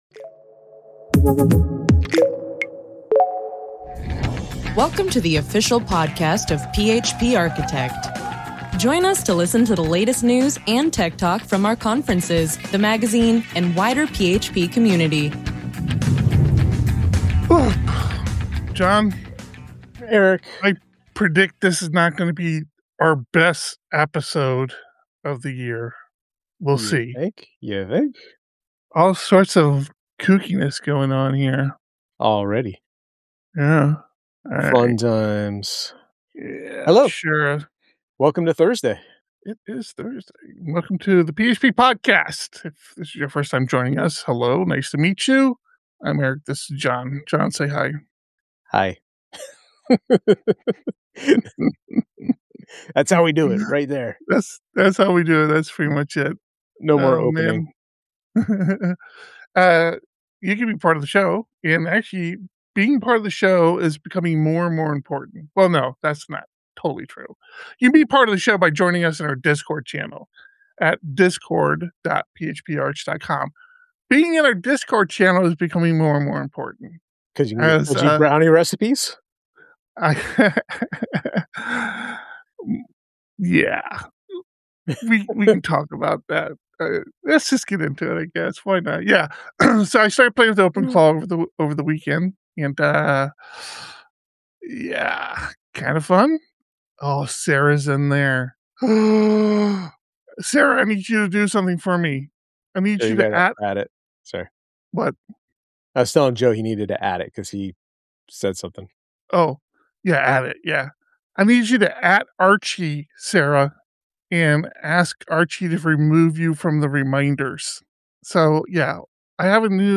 The PHP Podcast streams live, typically every Thursday at 3 PM PT.